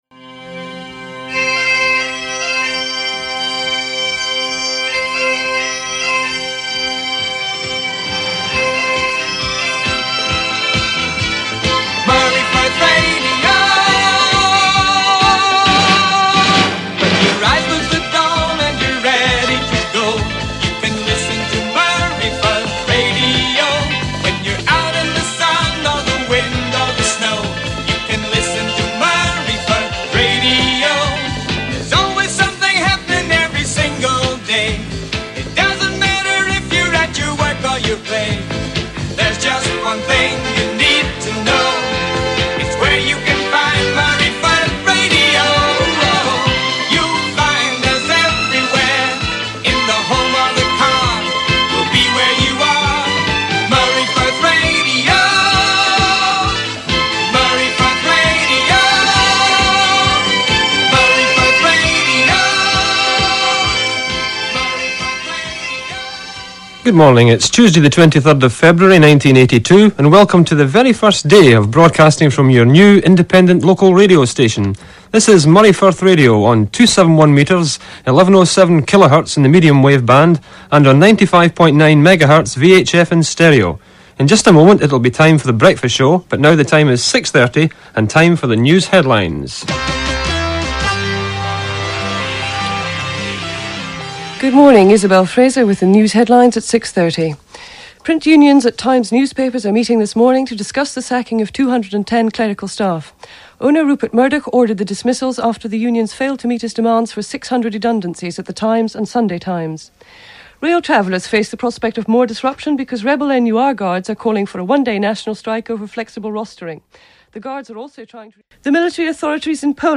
Hear here the launch of the great Moray Firth Radio in Inverness in 1982, one of the early smaller stations. Whilst a small operation, it covered a huge geographic area.